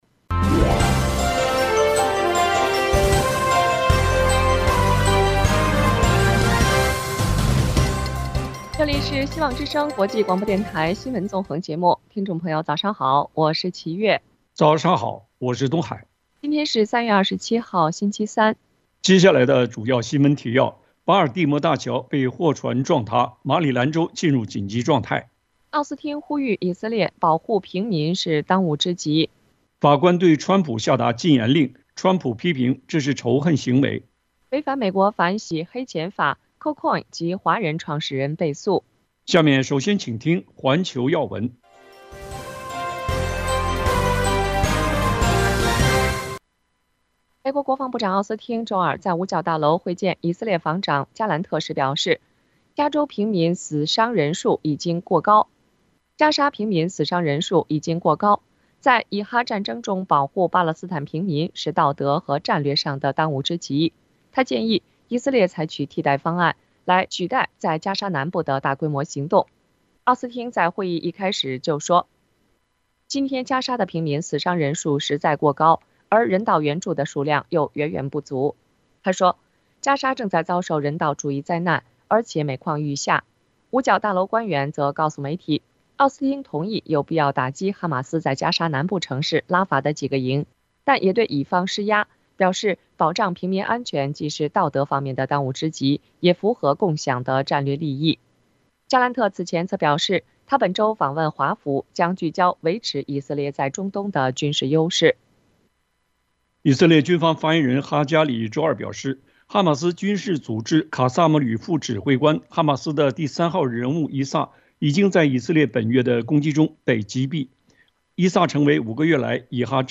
内塔尼亚胡：以色列不会屈服于哈马斯的“妄想要求”【晨间新闻】